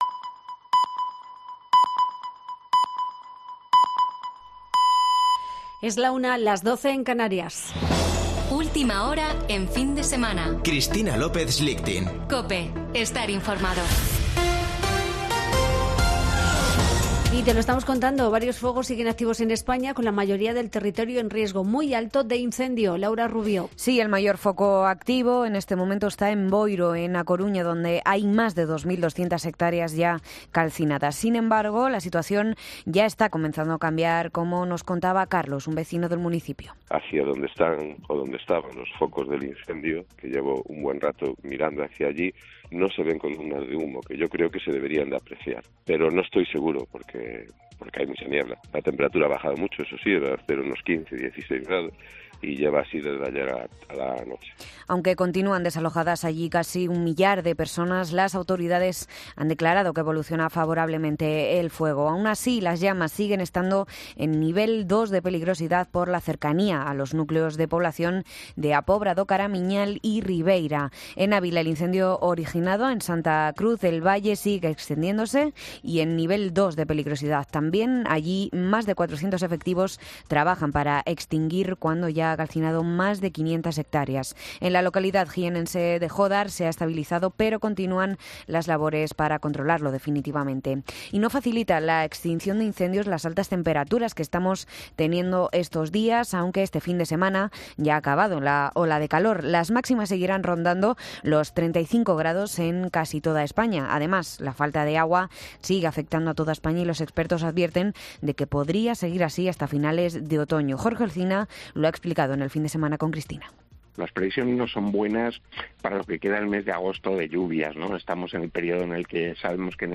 Boletín de noticias de COPE del 7 de agosto de 2022 a la 13.00 horas